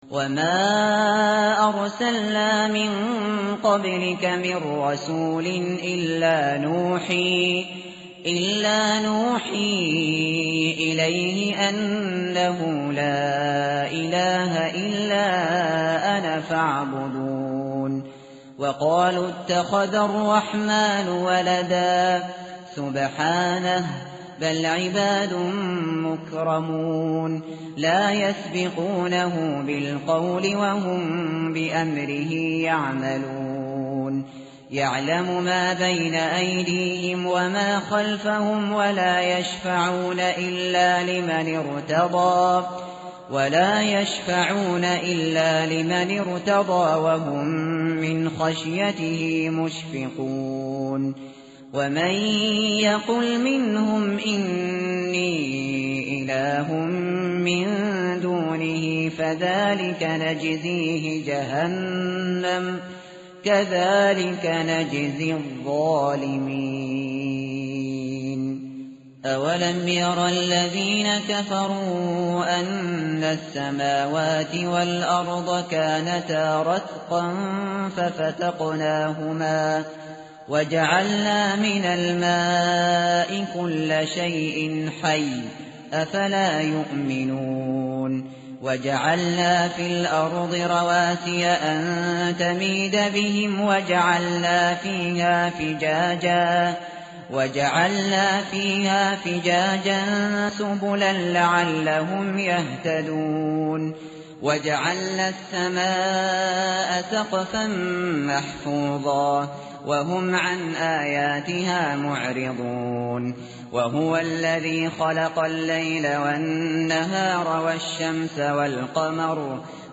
tartil_shateri_page_324.mp3